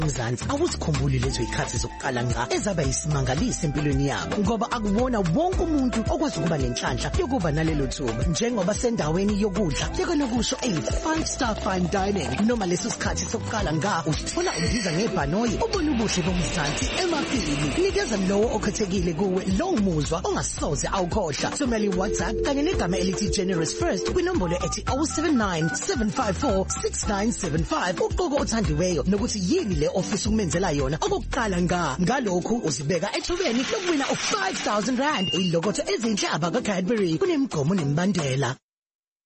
Advert: Win Competition